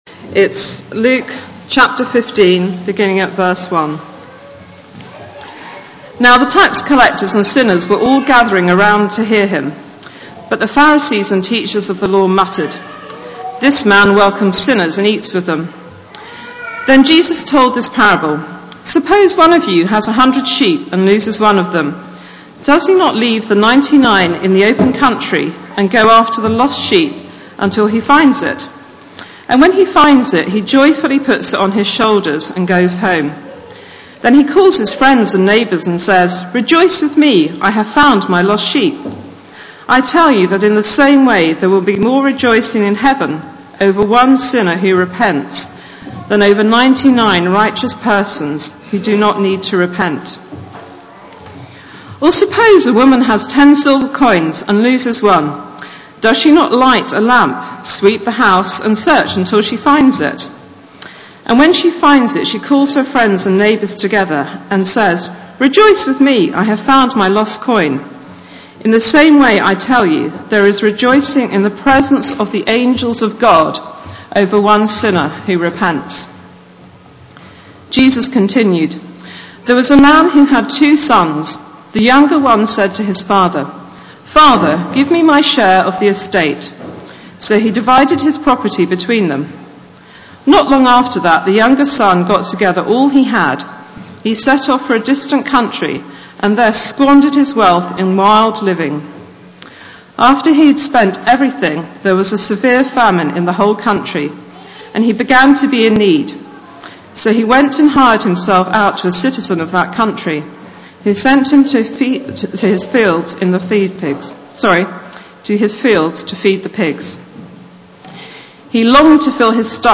Luke 15:1-32 – Baptism Service